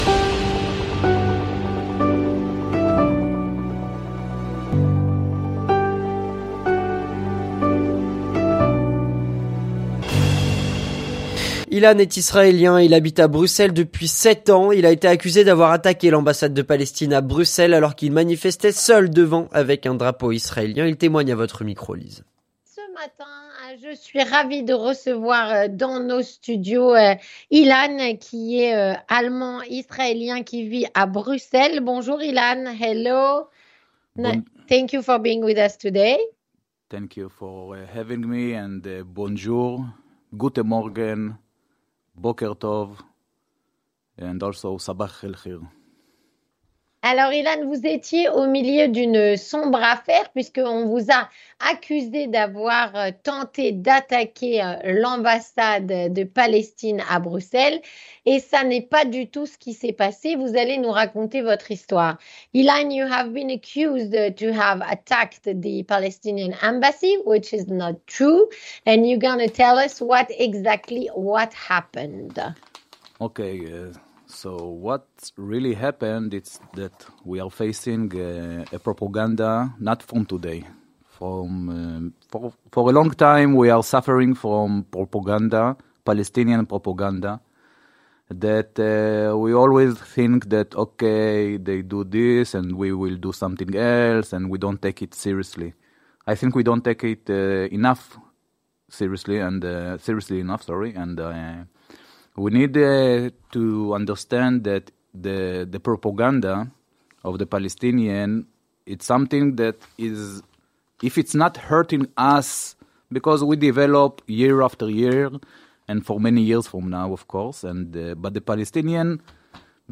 Il témoigne.